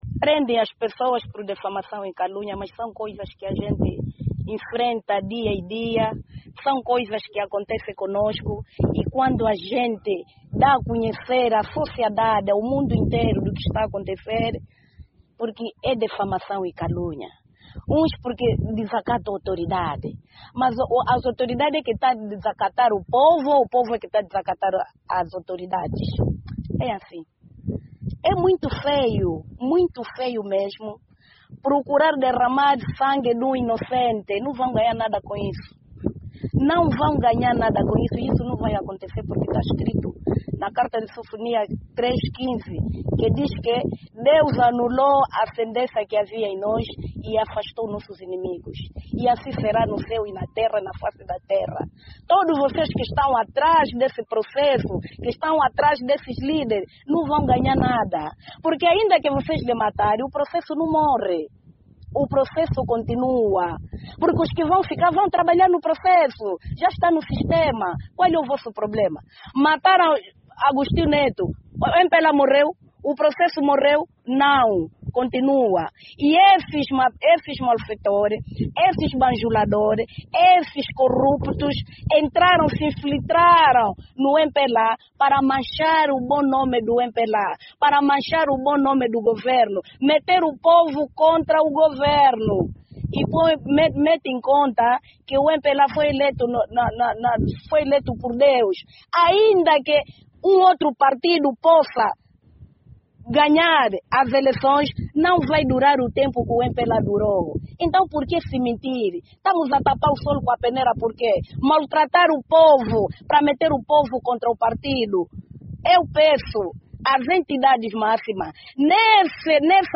Durante a conferência de imprensa, os trabalhadores manifestaram indignação com o que chamam de “má-fé das autoridades”.